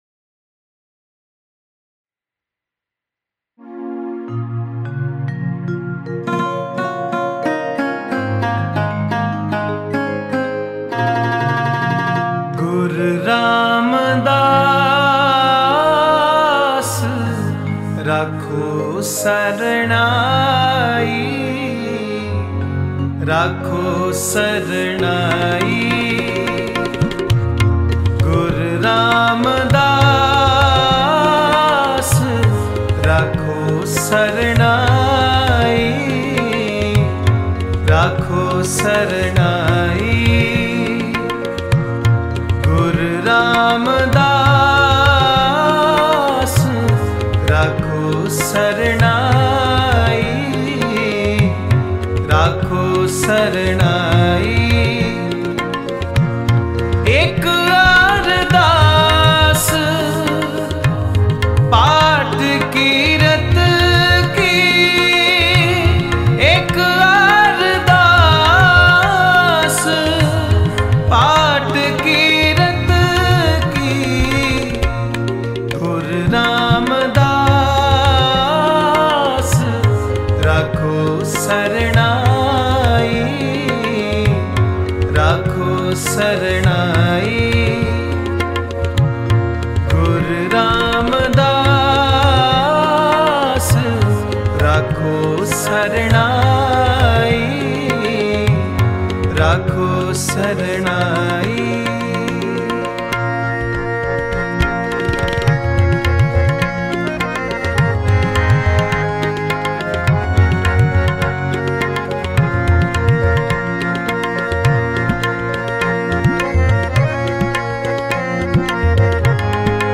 Mp3 Files / Gurbani Kirtan / 2025 Shabad Kirtan /